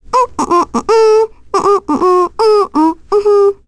Requina-vox-Hum.wav